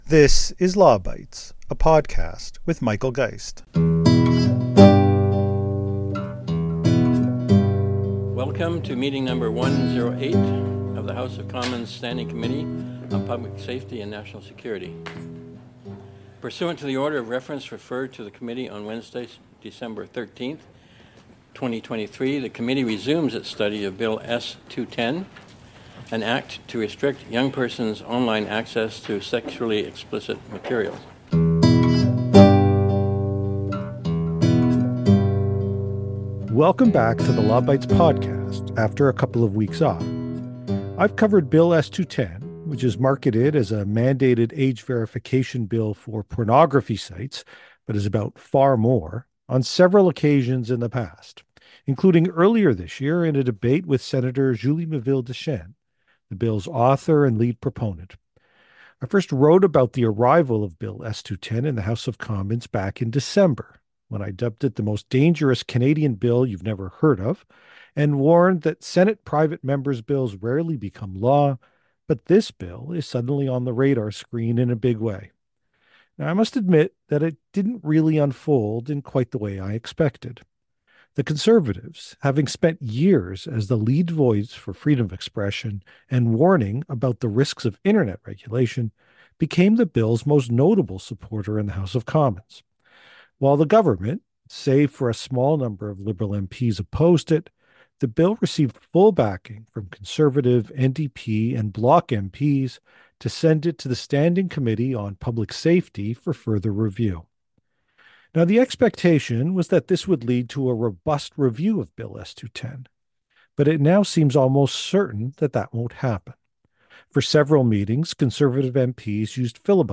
Before that vote, this week’s Law Bytes podcast offers up a “what could have been” hearing on the bill. It features my mock opening statement alongside responses to some of the actual questions raised by MPs on issues such as privacy, website blocking, and poorly defined terms in the bill.